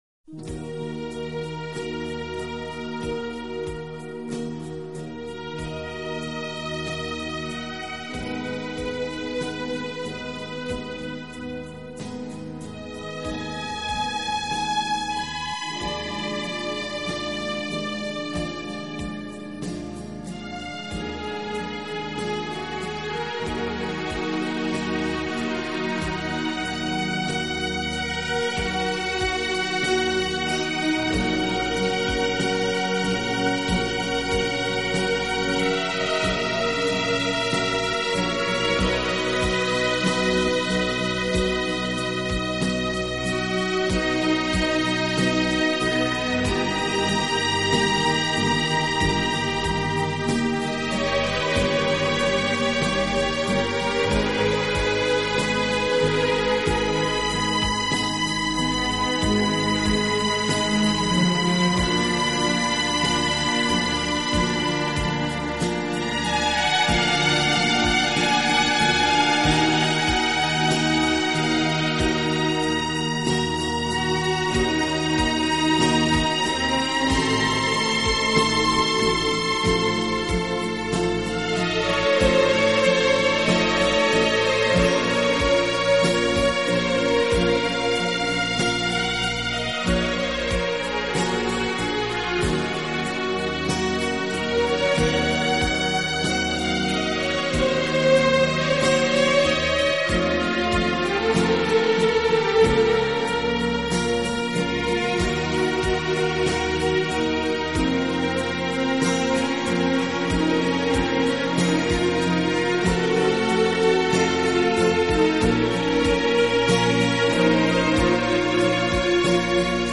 【顶级轻音乐】
录制方式：ADD
这张专辑运用了一些不常用的器乐，增加了神秘感和趣味感。